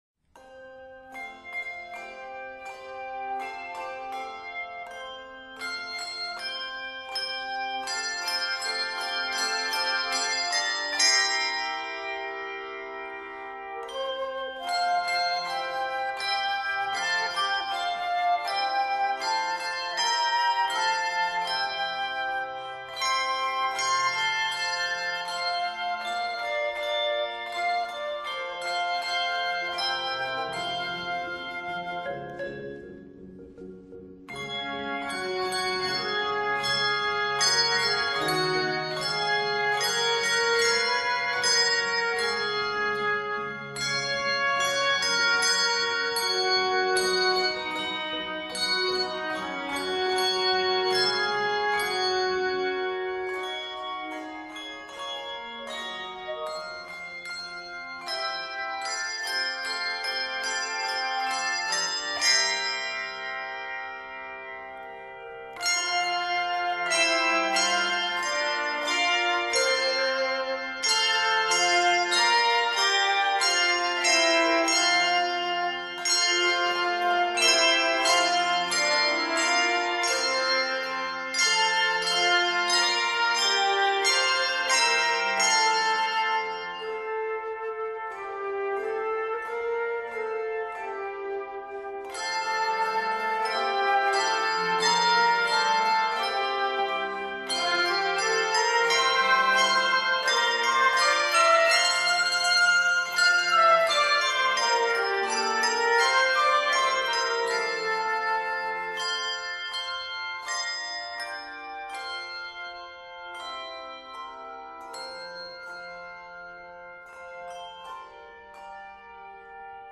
Set in F Major and Gb Major